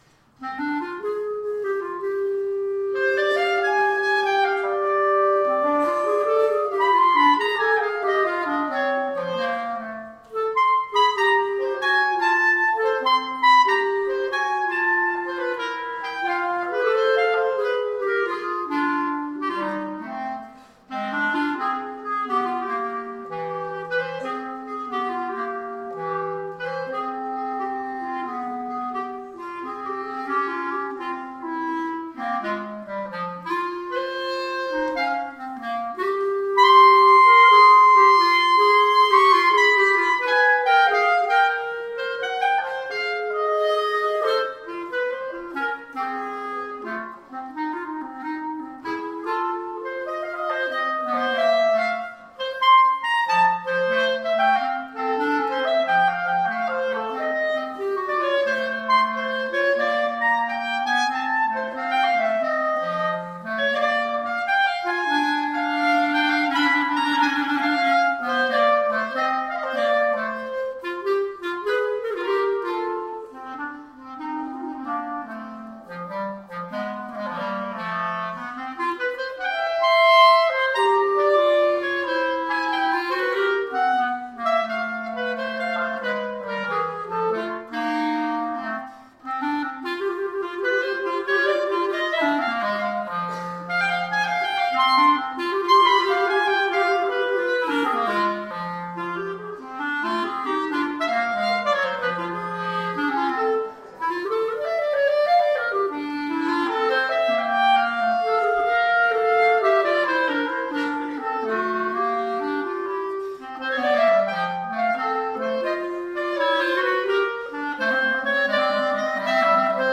They are from the coffee house event we held as a fundraiser at Mount Calvary back in may of 2010, so they’re about 3 years old, but just now seeing the light of day.
clarinet